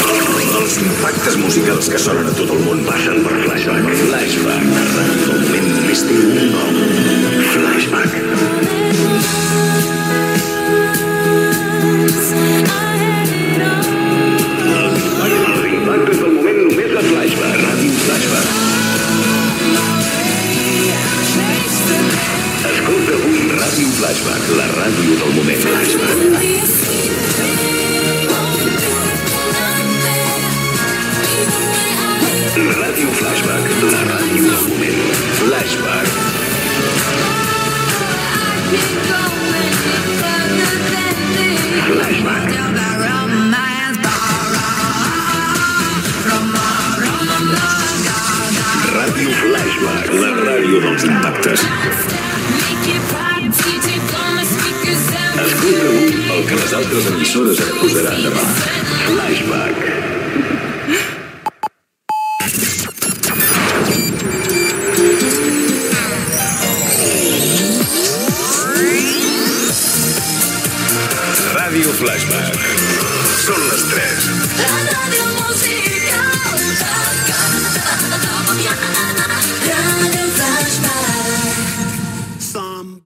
Indicatiu de l'emissora.
Indicatius "els impactes del moment" i de l' hora.